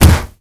pgs/Assets/Audio/Punches/punch_heavy_huge_distorted_03.wav
punch_heavy_huge_distorted_03.wav